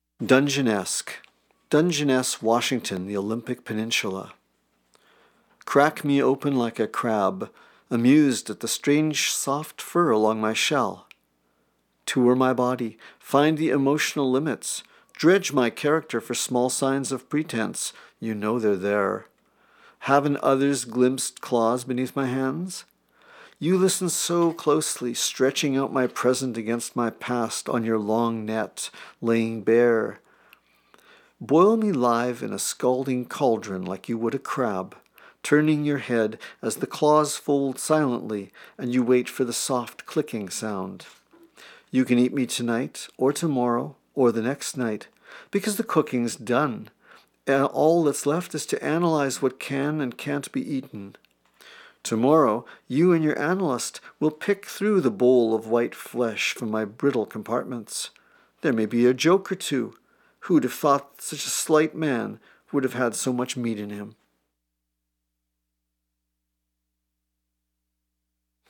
Poetry
reading the title poem from 'Dungenessque' (1:09)